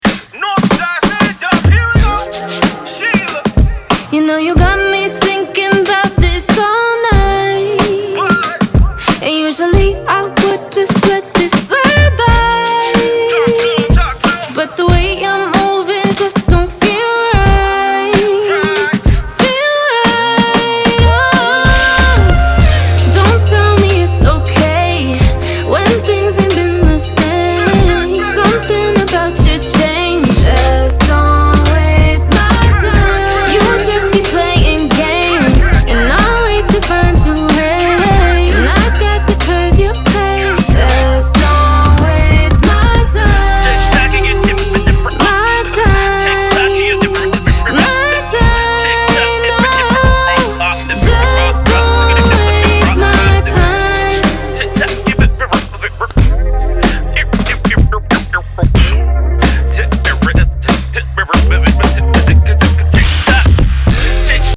R&B, Soul, 90s
F min